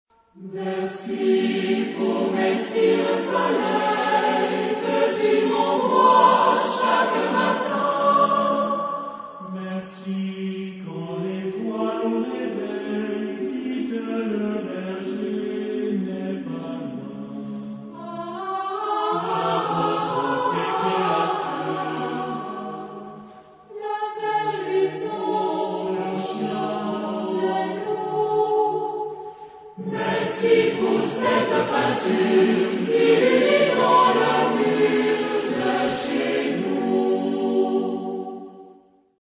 Genre-Style-Forme : Sacré ; Profane ; Variations
Caractère de la pièce : joyeux ; adorant
Type de choeur : SATB  (4 voix mixtes )